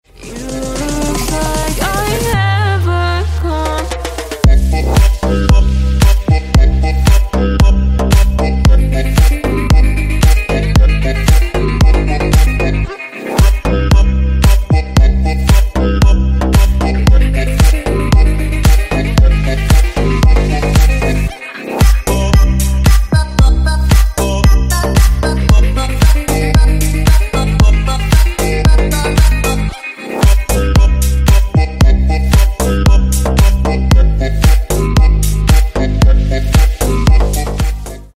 Клубные Рингтоны » # Громкие Рингтоны С Басами
Рингтоны Электроника